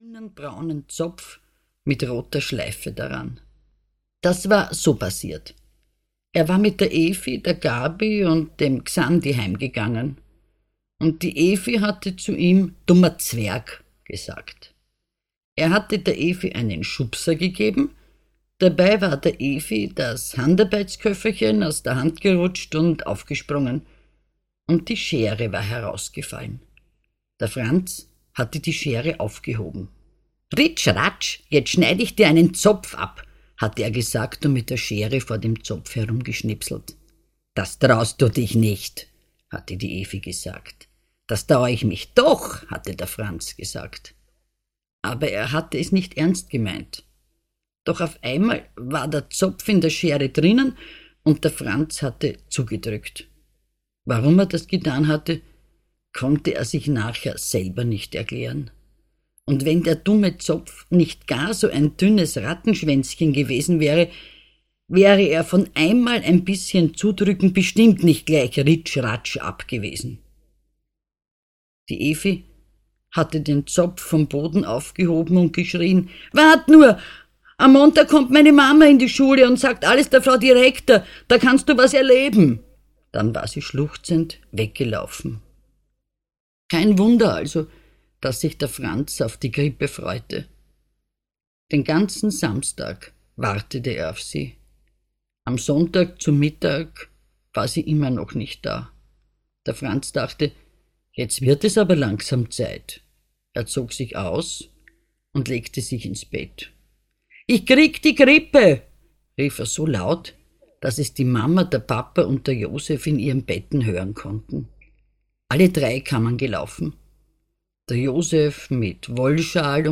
Krankengeschichten vom Franz - Christine Nöstlinger - Hörbuch